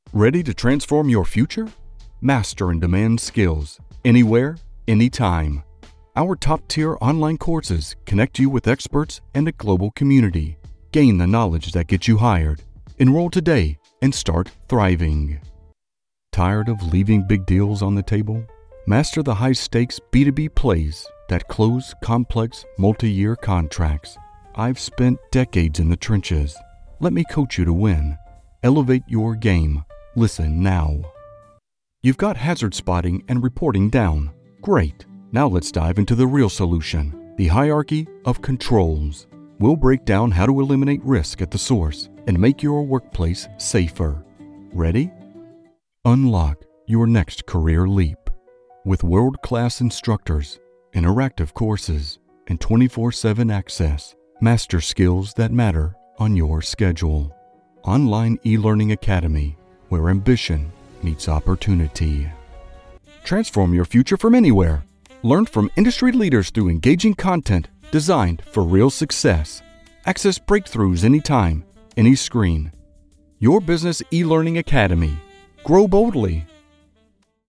male voiceover artist with a rich, deep, and exceptionally smooth vocal tone.
eLearning
General American, Southern